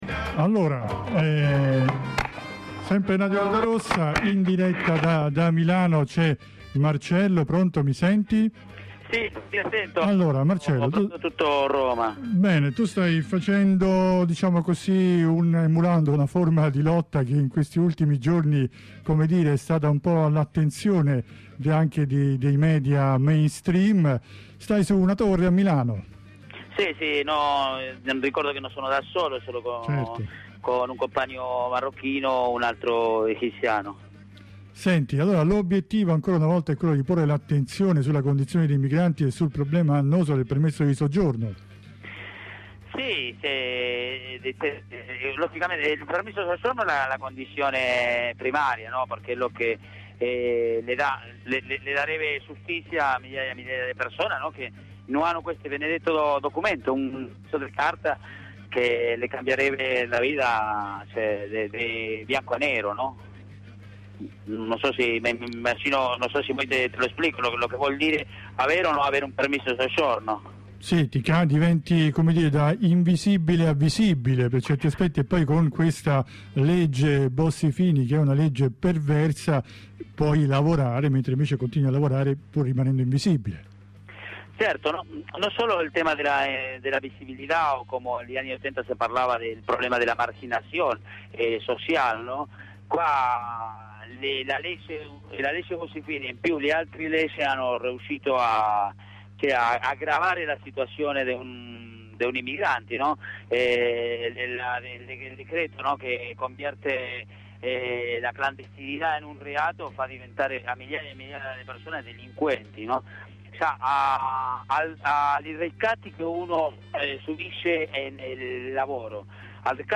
uno dei tre migranti sulla torre.